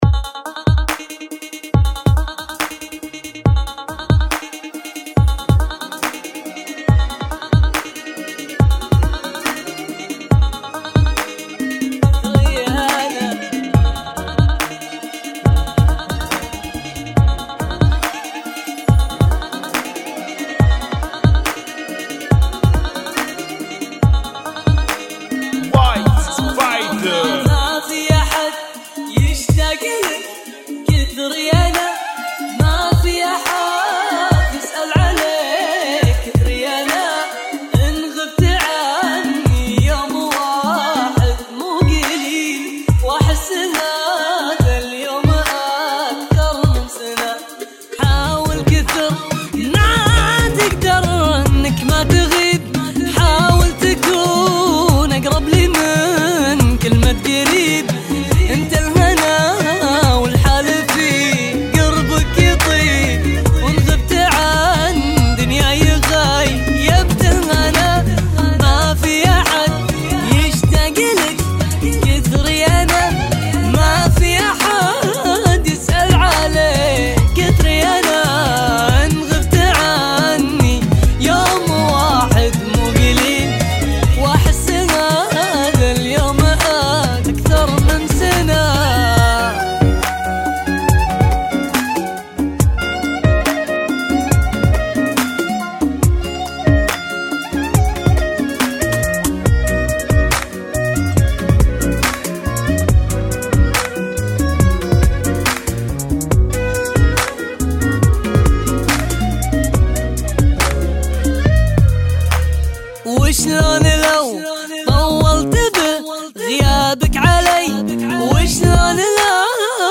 Funky [ 70 Bpm ]